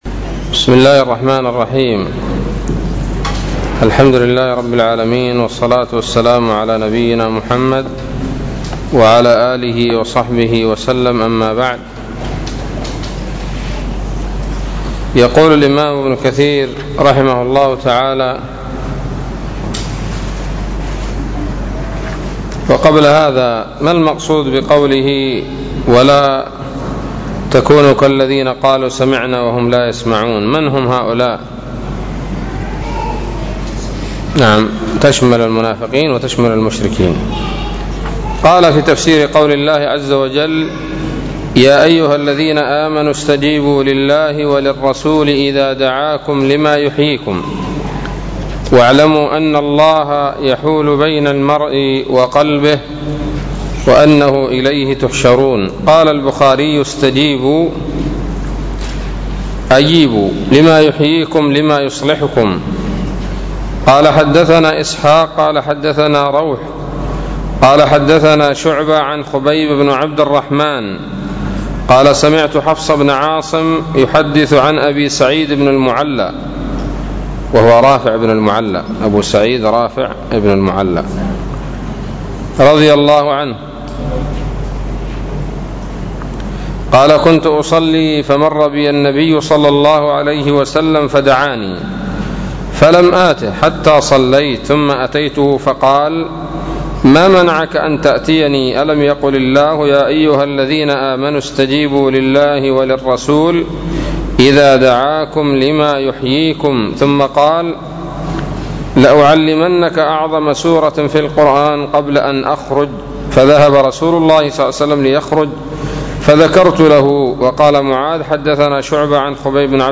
الدرس الثاني عشر من سورة الأنفال من تفسير ابن كثير رحمه الله تعالى